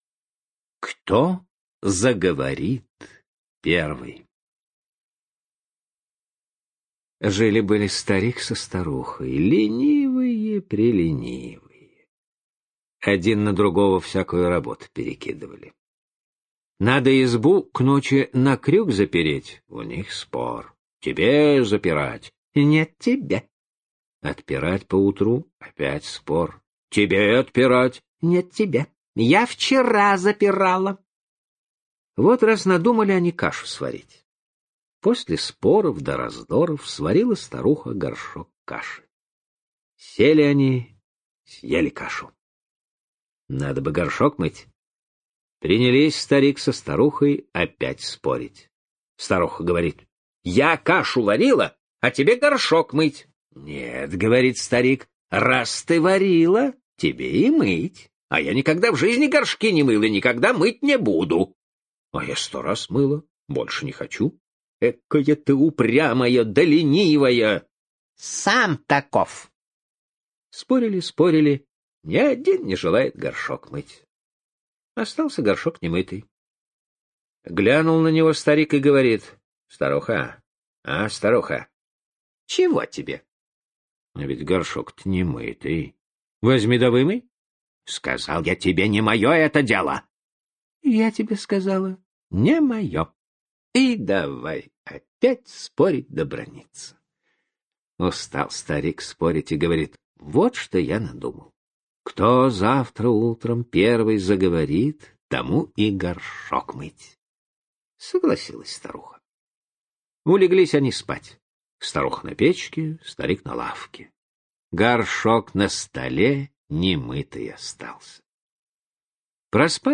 Кто заговорит первый: аудиосказка, слушать аудио для детей на ночь - Сказки РуСтих
Сказки для детей > Аудиосказки > Русские народные аудиосказки > Кто заговорит первый Кто заговорит первый: Слушать A Уменьшить шрифт.